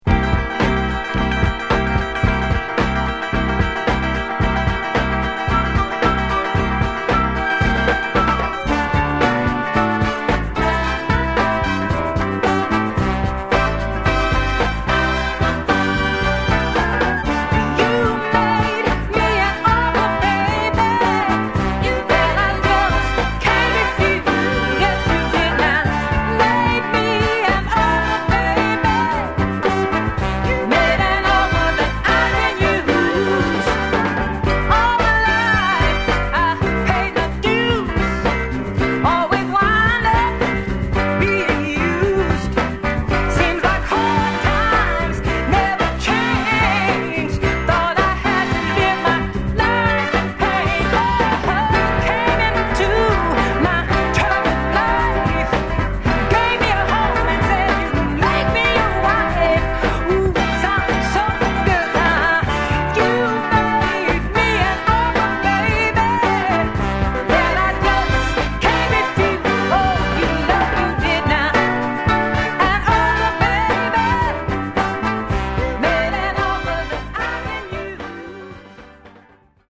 Genre: Northern Soul, Motown Style
An irresistible, Motown-style soul rarity.